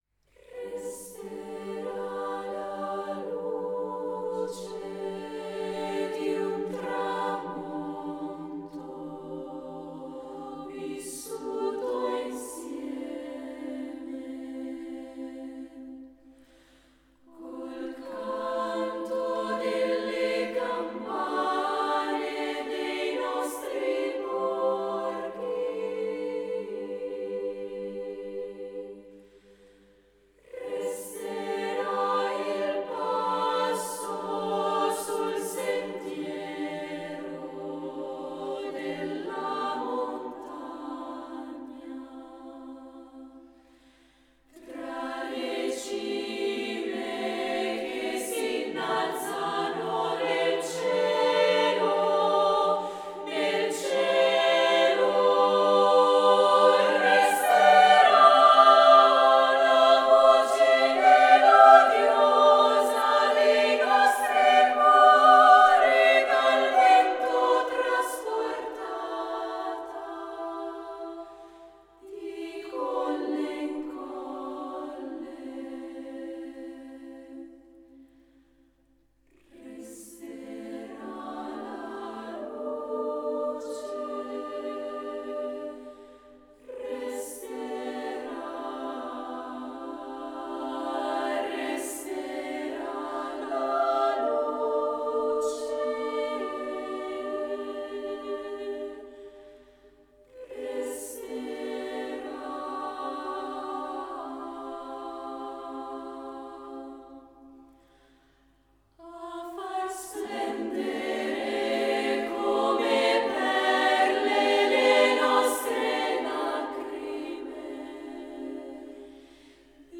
Voicing: SSAA* a cappella